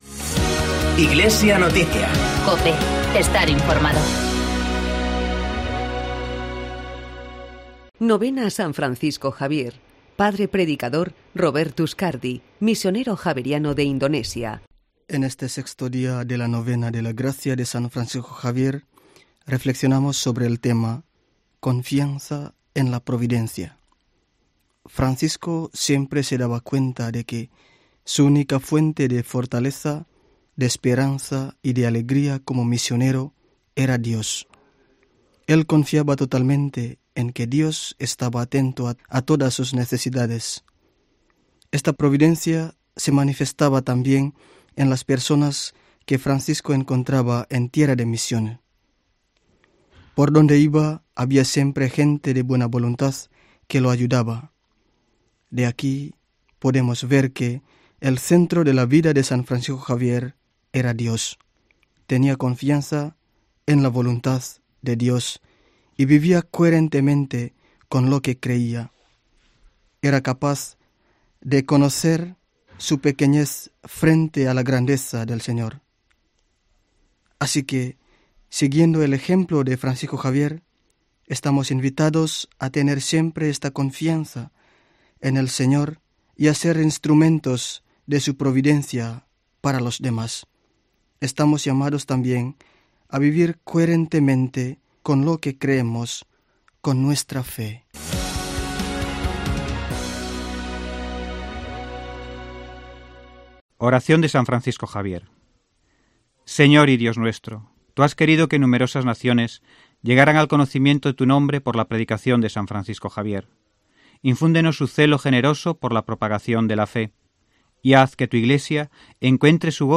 Padre predicador